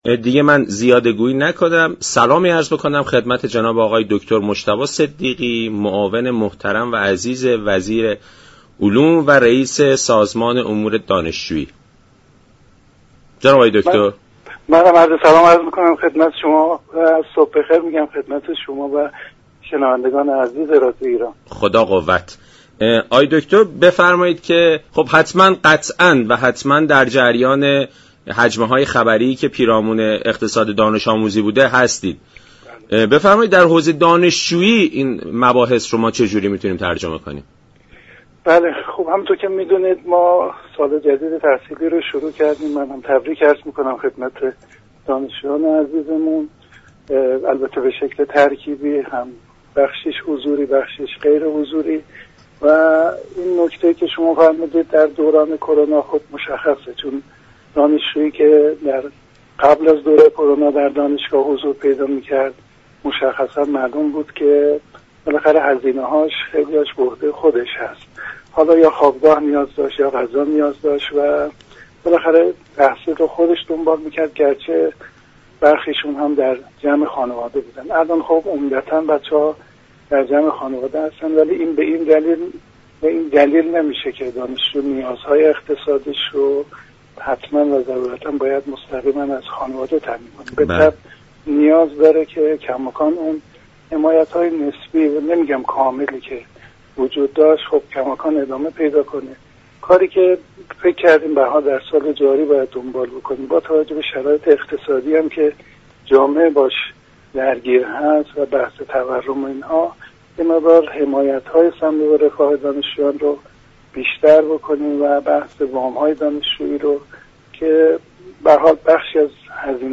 معاون وزیر علوم در برنامه سلام صبح بخیر گفت: سازمان امور دانشجویی تلاش می كند با ارائه وام و تسهیلات بخشی از هزینه های دانشجویان را تامین كند.